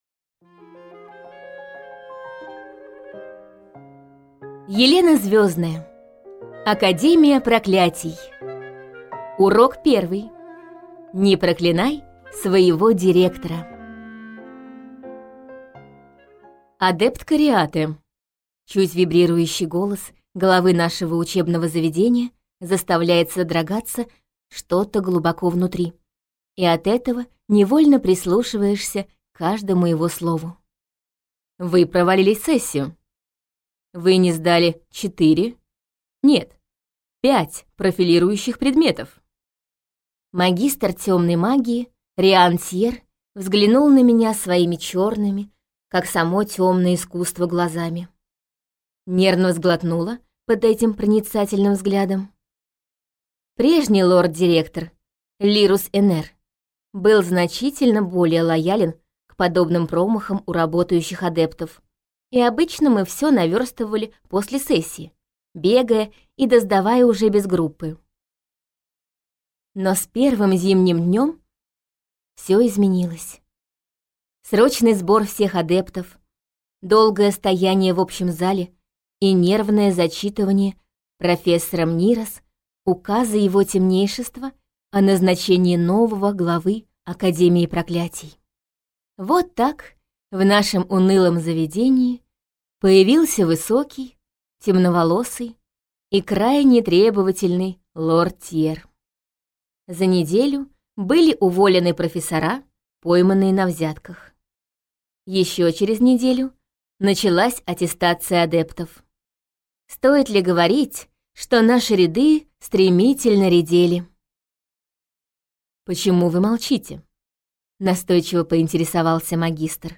Аудиокнига Урок первый: Не проклинай своего директора | Библиотека аудиокниг